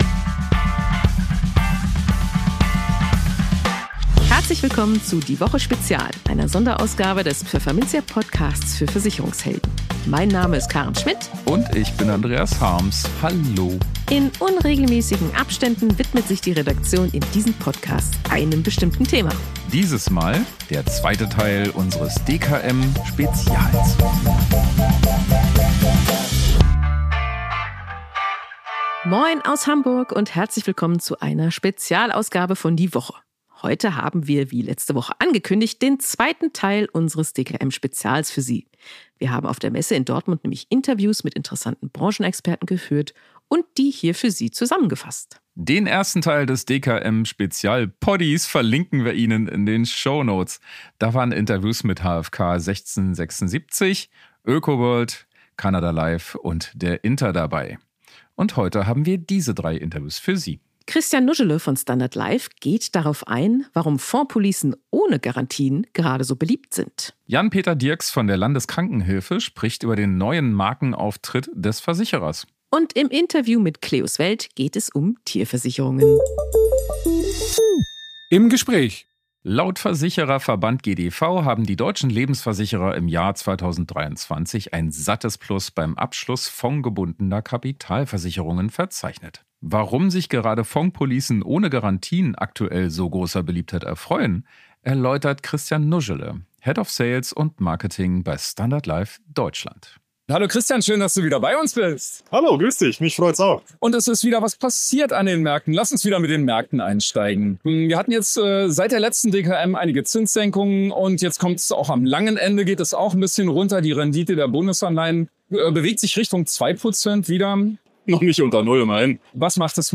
Heute haben wir wie angekündigt den zweiten Teil unseres DKM-Spezials für Sie. Wir haben auf der Messe in Dortmund Interviews mit interessanten Branchenexperten geführt – und die hier für Sie zusammengefasst.